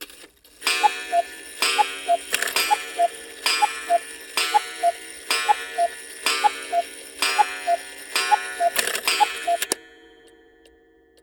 cuckoo-clock-10.wav